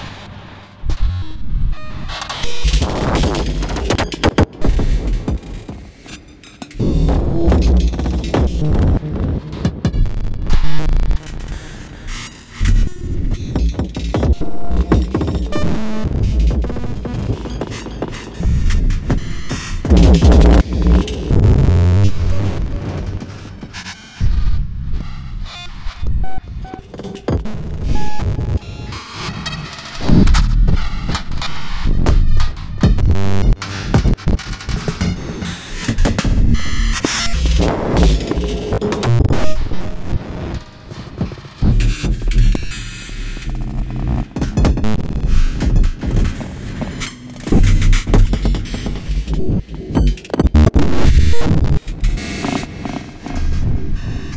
Jamming the new V0 drums …
No plugins , only non-stock thing was an impulse response loaded into convolution
Mostly note repeats that were resampled, and then the sample played back with random pitches in granular mode…that was then run through Delay+ and Filter+ (for the waveshaper)
no problemo – and of course a liberal dash of modulation from and to everywhere